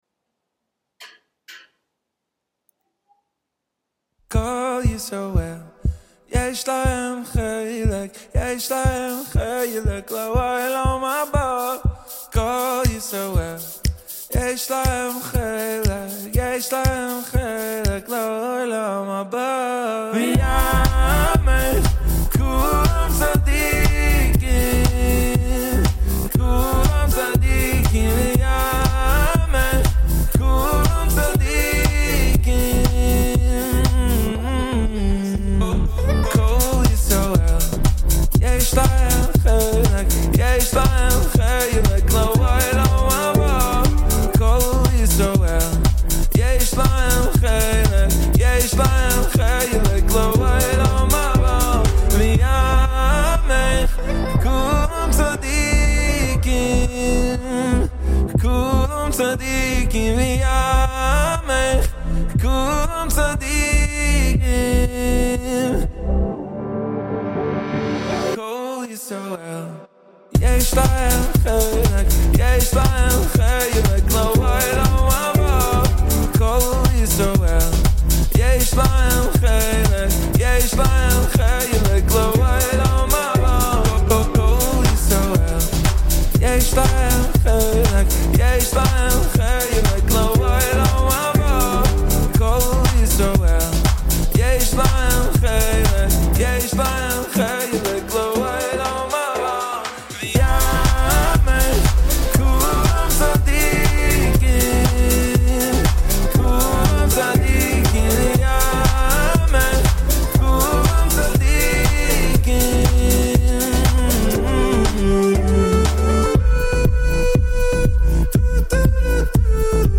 a wonderful Christmas Eve day show for you all today with great jazz, world music and lots more!